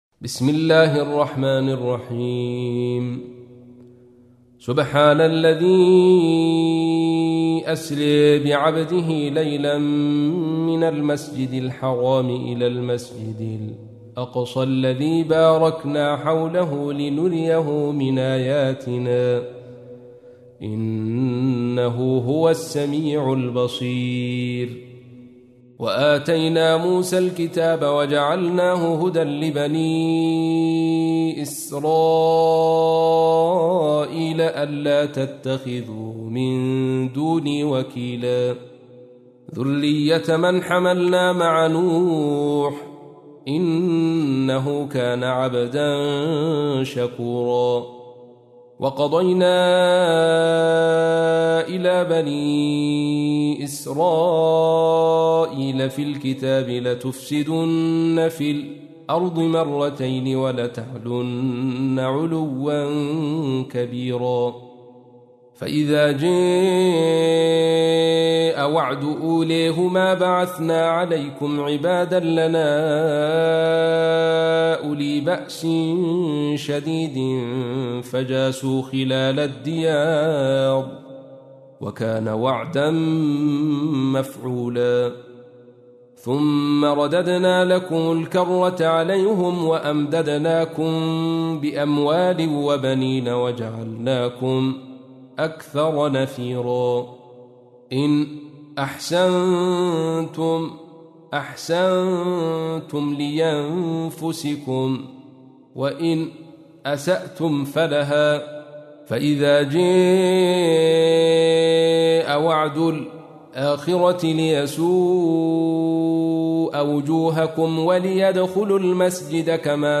تحميل : 17. سورة الإسراء / القارئ عبد الرشيد صوفي / القرآن الكريم / موقع يا حسين